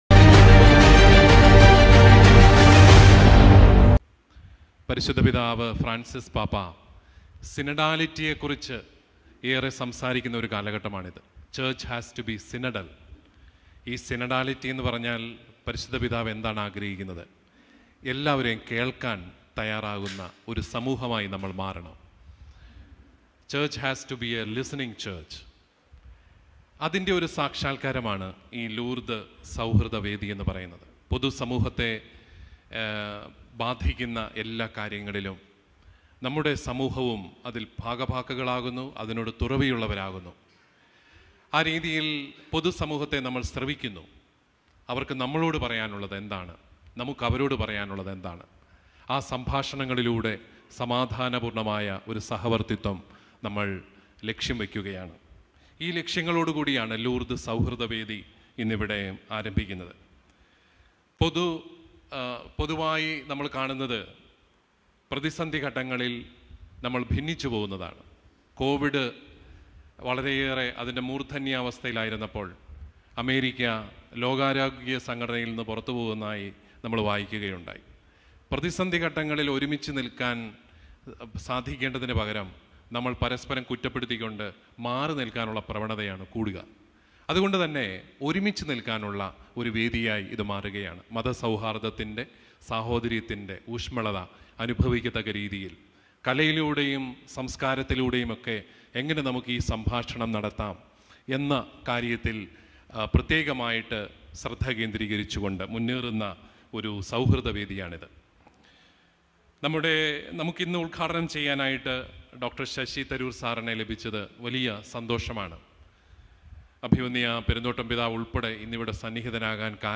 Automatic Speech Recognition
Bishop Thomas Tharayil speaks about Dr Shashi Tharoor at Lourdes Forane Church Thiruvananthapuram.wav